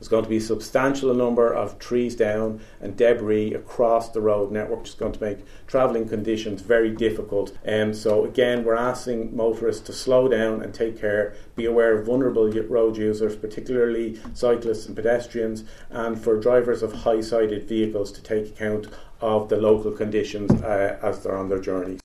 National Director of Fire and Emergency Management, Keith Leonard has this advice for motorists: